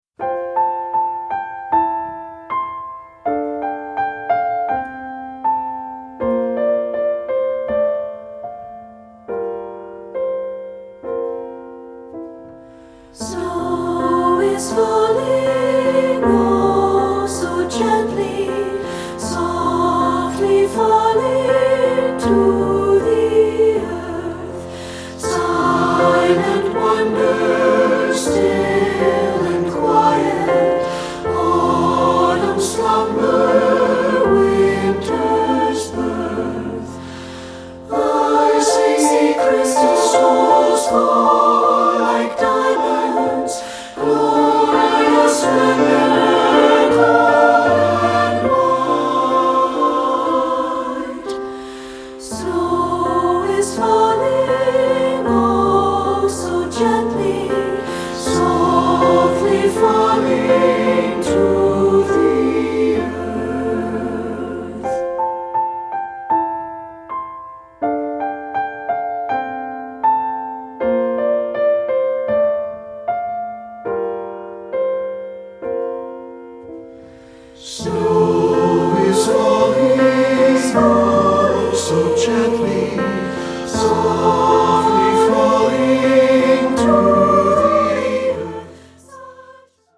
Choral Concert/General
3 Part Mix